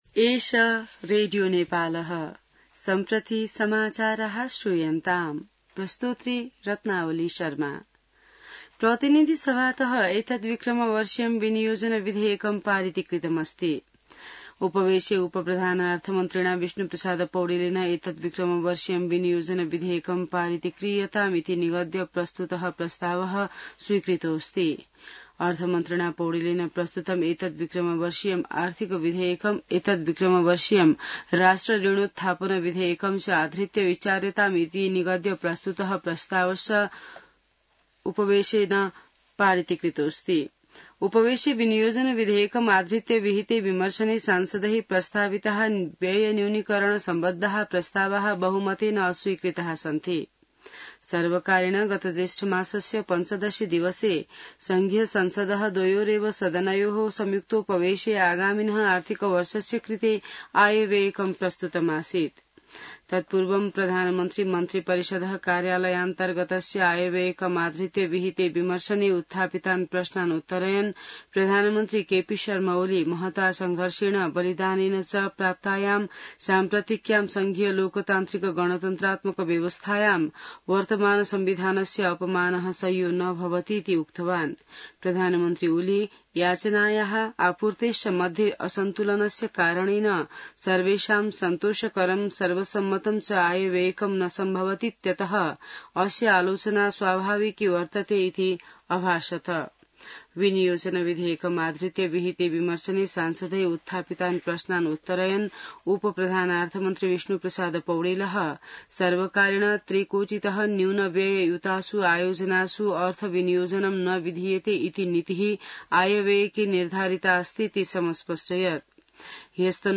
संस्कृत समाचार : ११ असार , २०८२